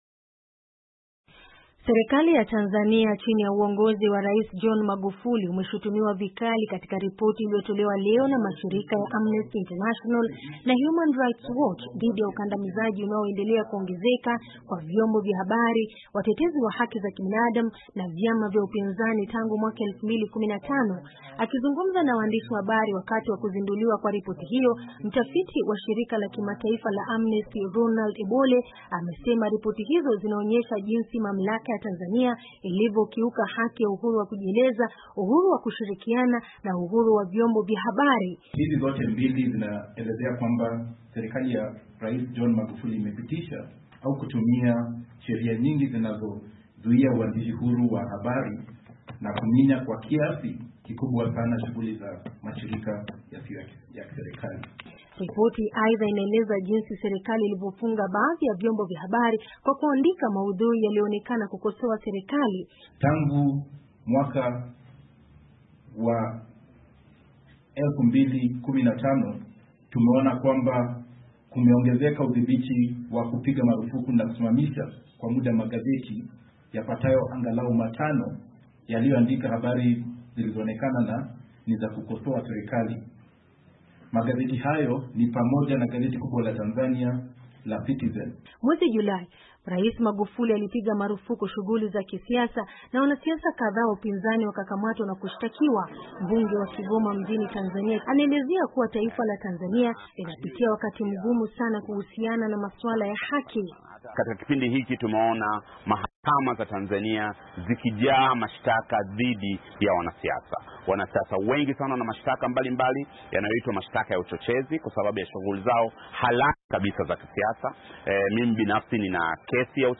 Ripoti ya Amnesty, Human Rights Watch
Mbunge wa Kigoma Mjini nchini Tanzania Zitto Kabwe anaelezea kuwa taifa la Tanzania linapitia wakati mgumu sana kuhusiana na masuala ya haki za binadamu.